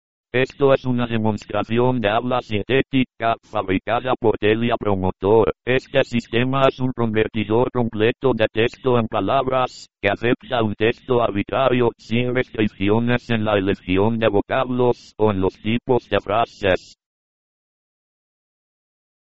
50. Spanish male voice. 0.17